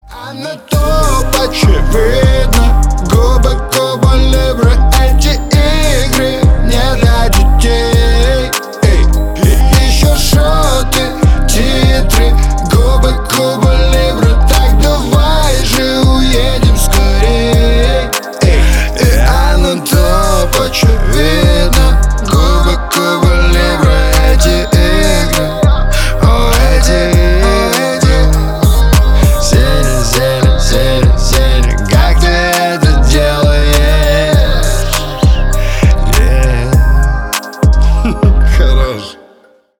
• Качество: 320 kbps, Stereo
Рэп и Хип Хоп